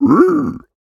Minecraft Version Minecraft Version 25w18a Latest Release | Latest Snapshot 25w18a / assets / minecraft / sounds / mob / piglin / jealous4.ogg Compare With Compare With Latest Release | Latest Snapshot
jealous4.ogg